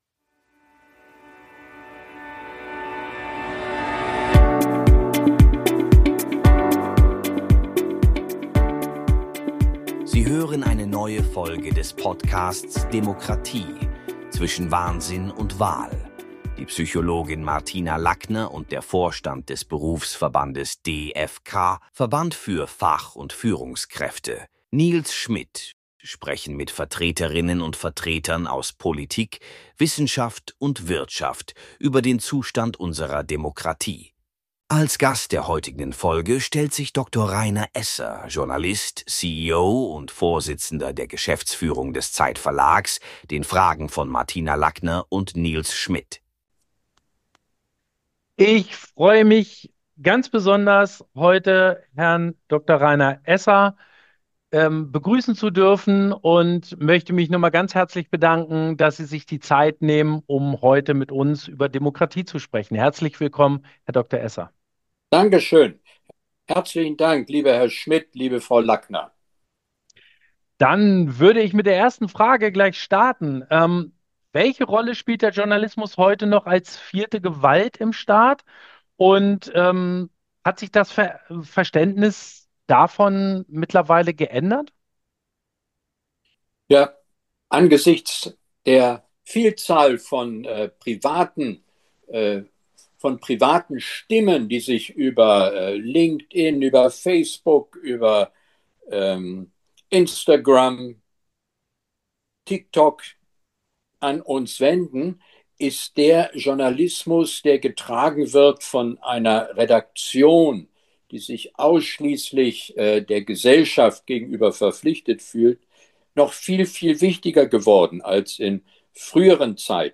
Wir sprechen mit ihm über die Bedeutung von Journalismus für die Demokratie, welche Rolle der Journalismus im Staat übernimmt und wieso er so gefordert ist und unter Druck steht wie noch nie. Auch über die Pressefreiheit in Deutschland im Vergleich zu anderen Ländern diskutieren wir ausführlich.